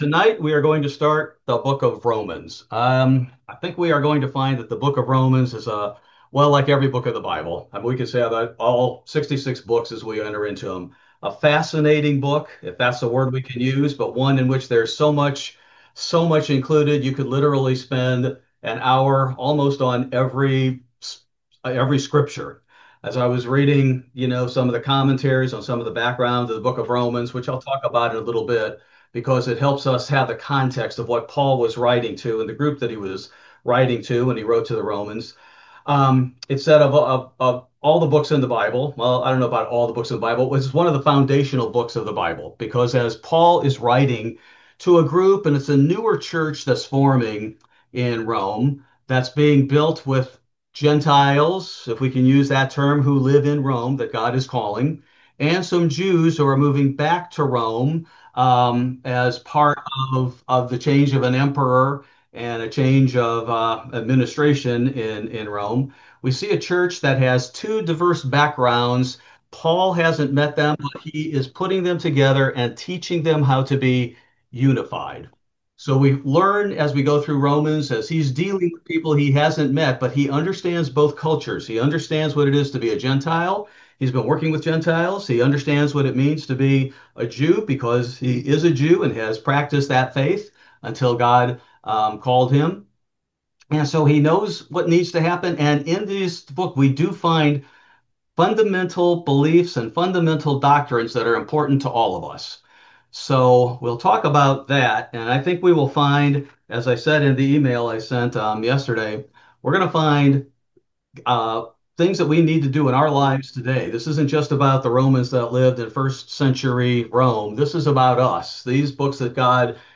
Zoom audience questions are included, as this is a live Bible study presented to a widespread Zoom audience.